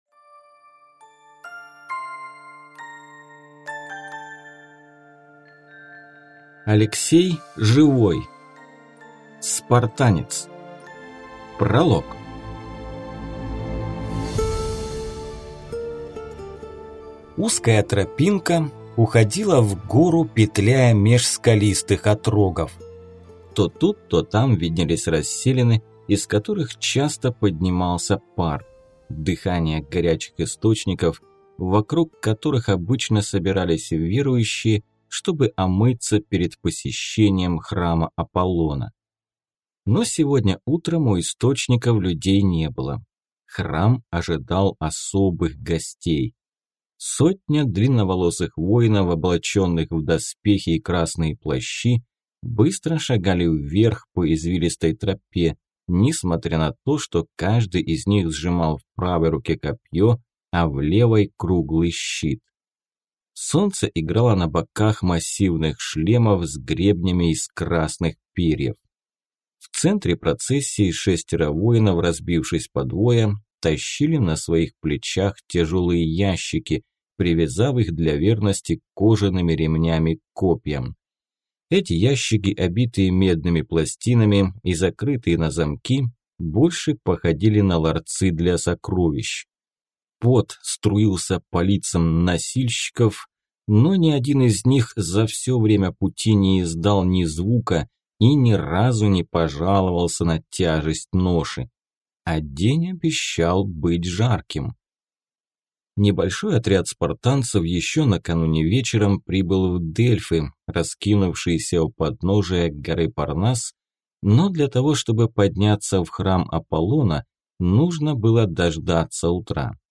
Аудиокнига Спартанец: Спартанец. Великий царь. Удар в сердце | Библиотека аудиокниг